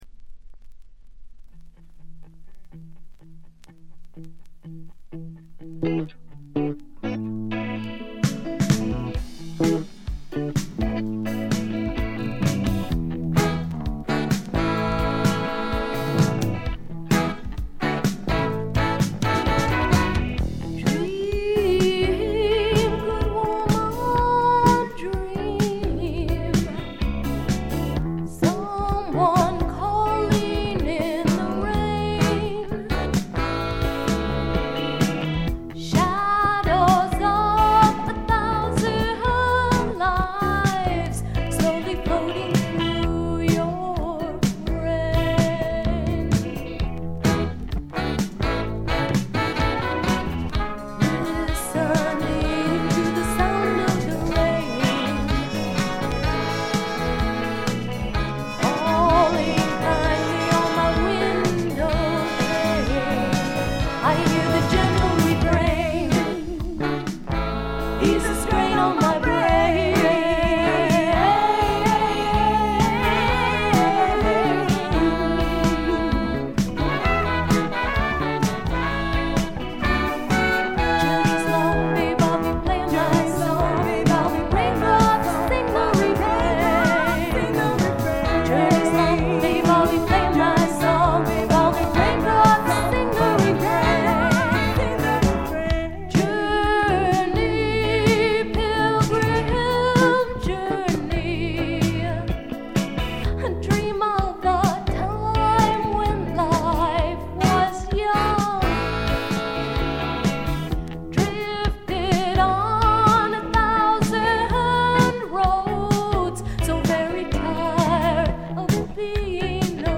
部分試聴ですが、軽微なバックグラウンドノイズにチリプチ少し。
70年代初頭の感覚が強い「あの感じの音」です。
試聴曲は現品からの取り込み音源です。